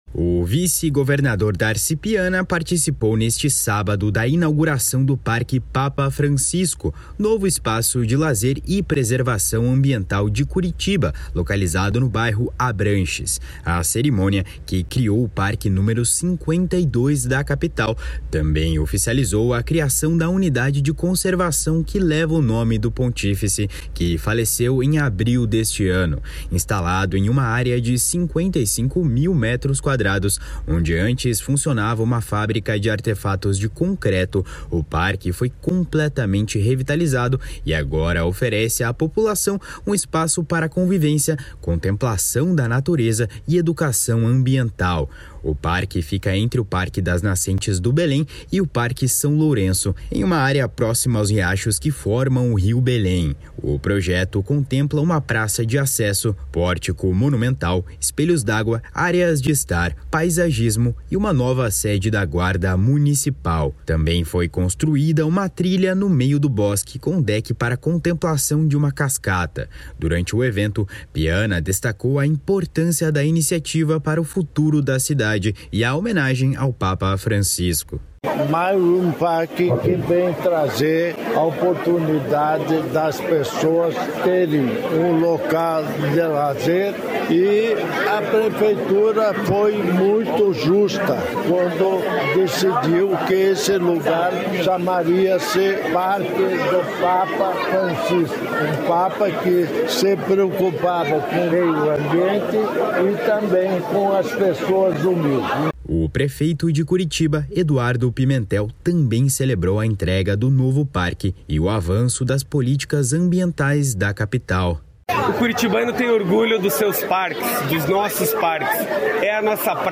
// SONORA DARCI PIANA //
O prefeito de Curitiba, Eduardo Pimentel, também celebrou a entrega do novo parque e o avanço das políticas ambientais da capital. // SONORA EDUARDO PIMENTEL ////